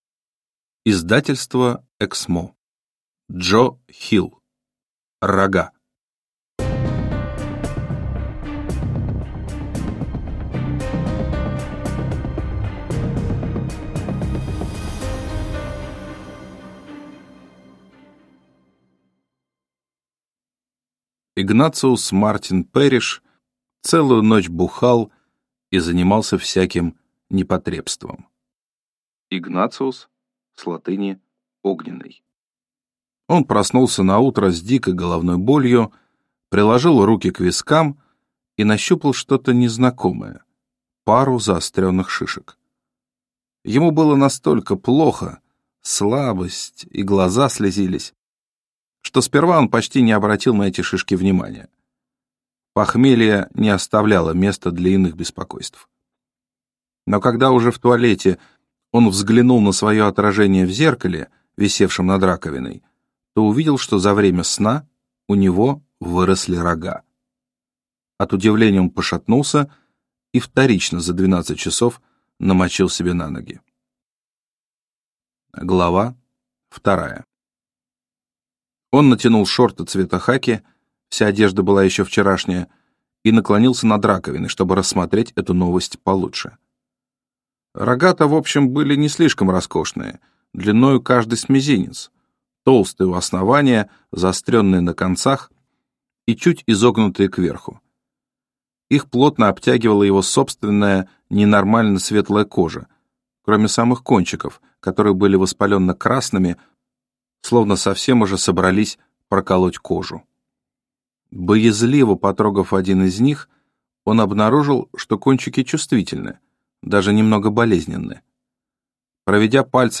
Аудиокнига Рога | Библиотека аудиокниг